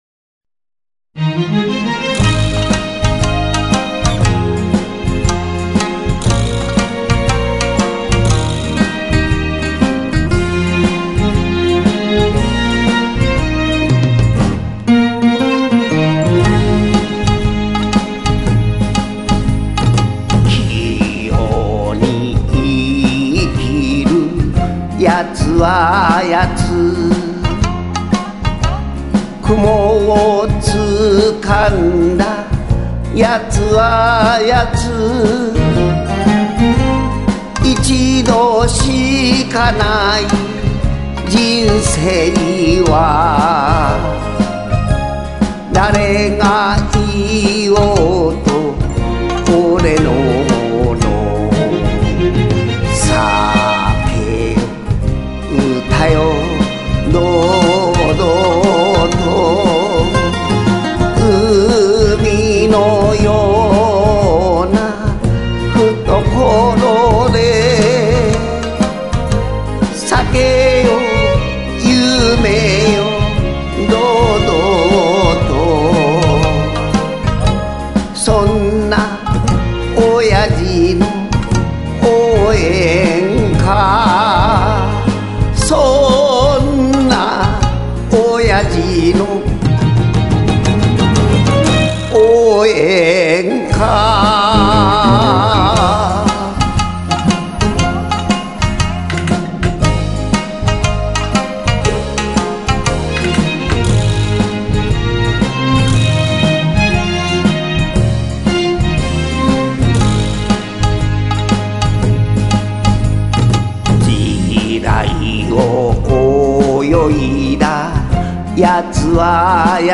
曲調は音頭の様な乗りのいい調子で、年配の方が歌い易くなってます。